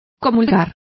Complete with pronunciation of the translation of communed.